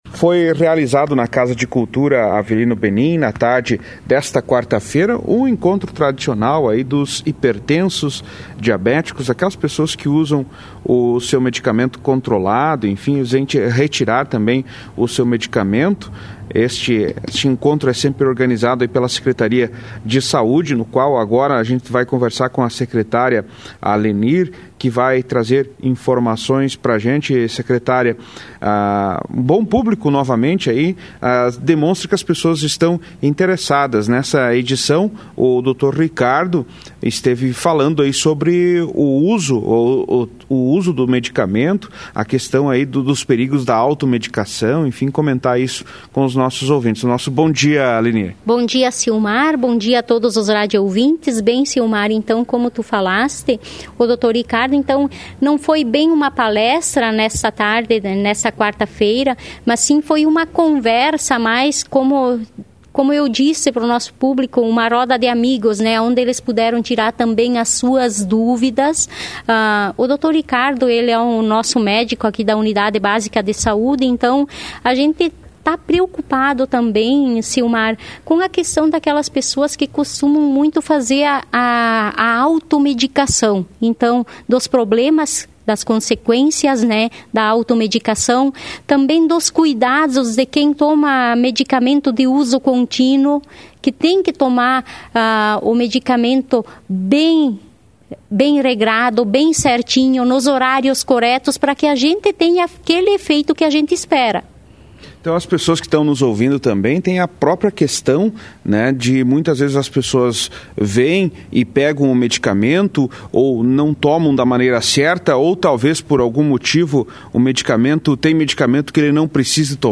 Ouça abaixo entrevista com a Secretária Municipal de Saúde Lenir: